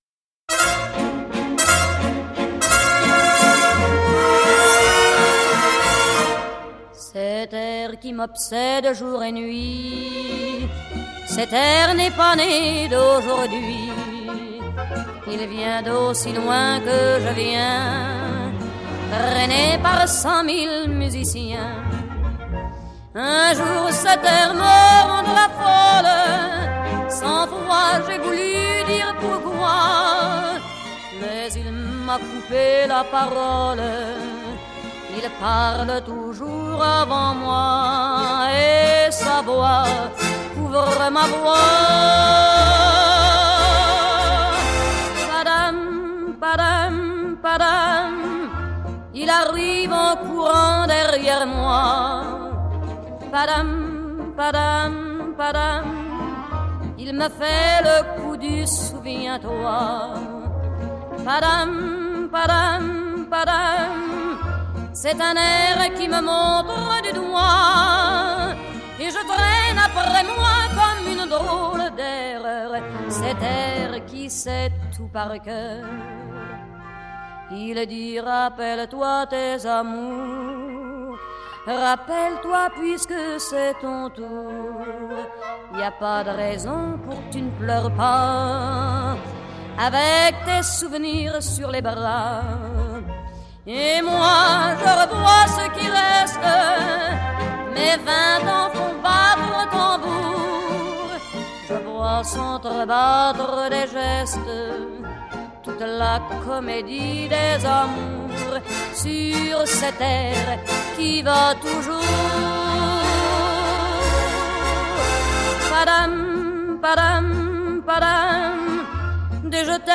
02 Viennese Waltz